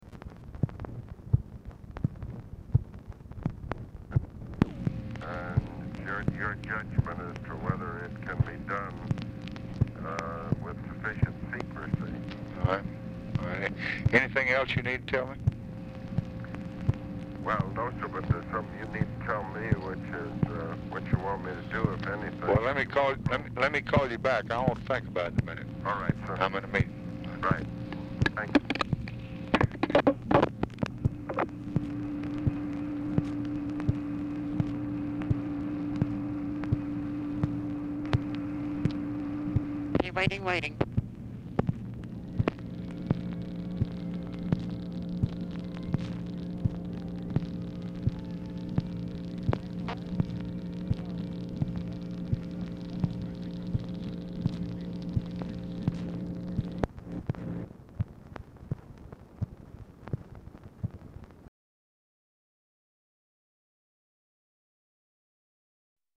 Telephone conversation # 7682, sound recording, LBJ and ABE FORTAS, 5/14/1965, 7:29PM | Discover LBJ
Format Dictation belt
Location Of Speaker 1 Oval Office or unknown location
Specific Item Type Telephone conversation Subject Defense Diplomacy Latin America